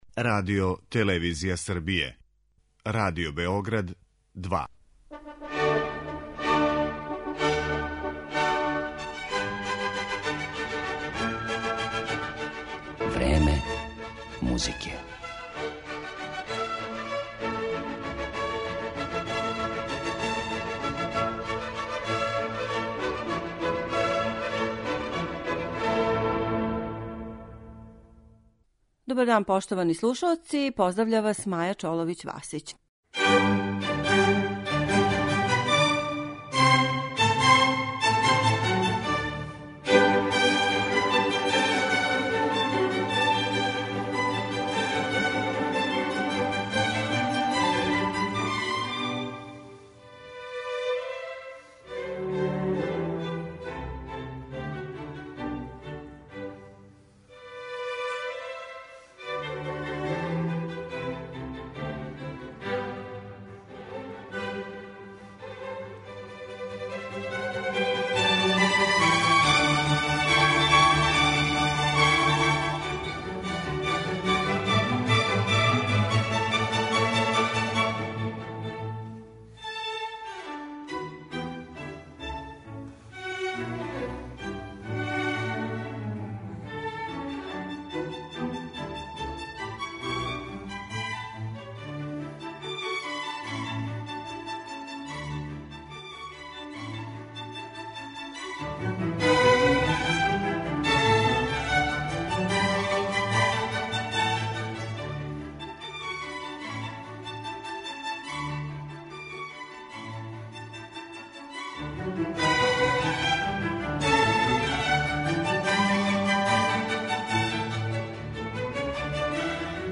У данашњој емисији је представљамо избором снимака музике Моцарта, Баха, Хајдна Менделсона...